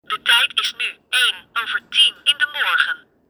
De tijd op het horloge wordt uitgesproken bij een druk op de 2-uurs knop van het horloge.
Tijd-Iris-sprekend-horloge.mp3